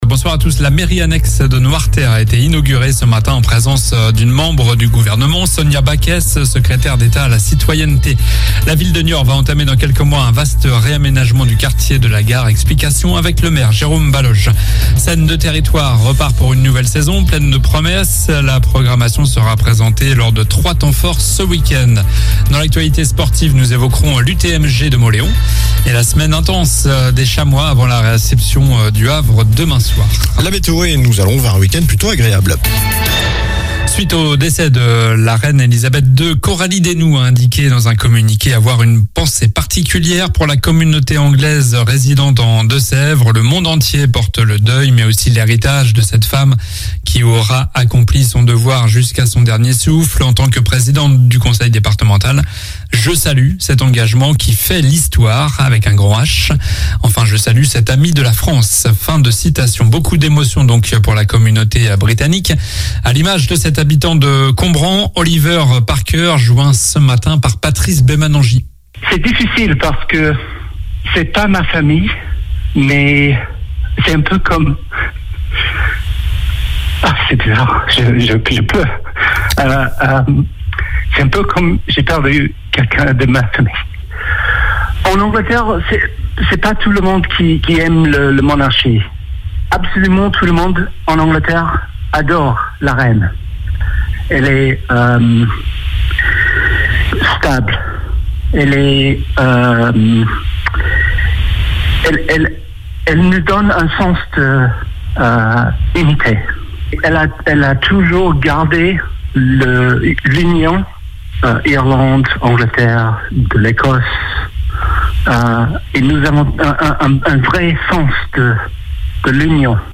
Journal du vendredi 9 septembre